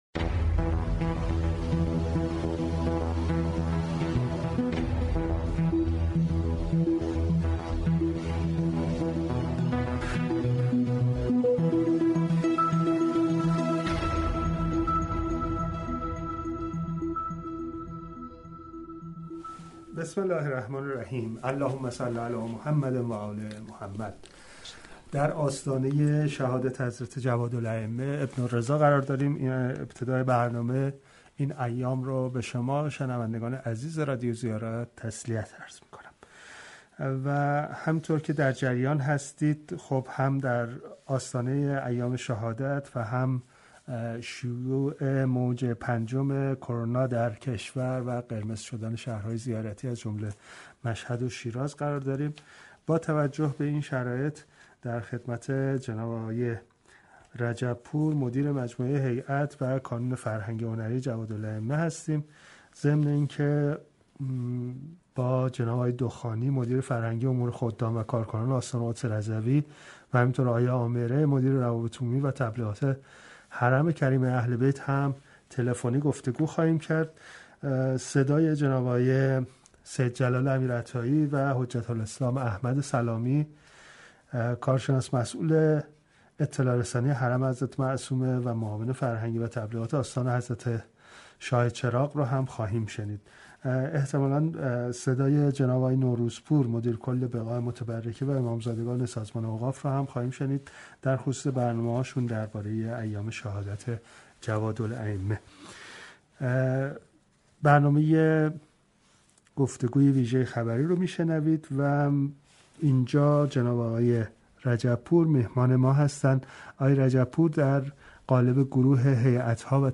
با شنیدن صوت گفتگوی ویژه خبری رادیو زیارت درباره این برنامه عزاداری و دیگر برنامه های آستان های مقدس مطلع خواهید.